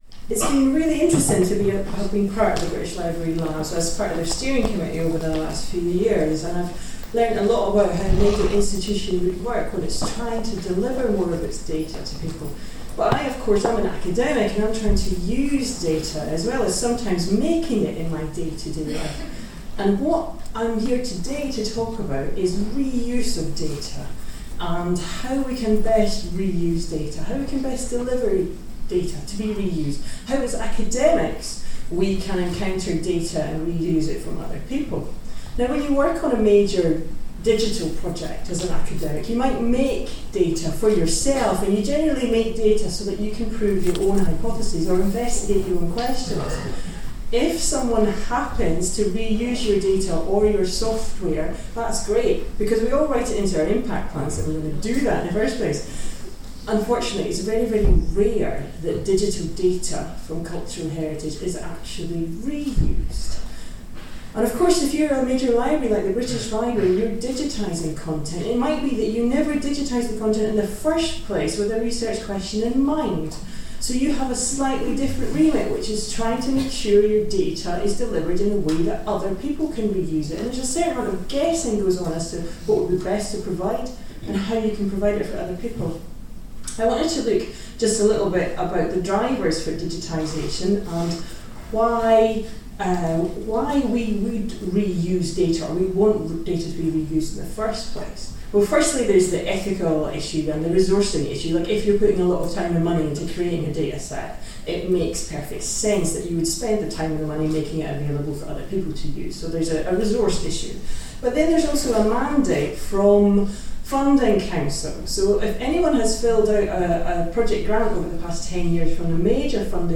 Next up was the British Library Lab’s Annual Symposium on November 7th. My talk was called ‘’Unexpected repurposing: the British Library’s Digital Collections and UCL teaching, research and infrastructure”. I highlighted how we have been using the British Library’s digitised book collection – 60,000 volumes which are now in the public domain – to explore processing of large scale digitised collections, both with researchers and computing science students at UCL.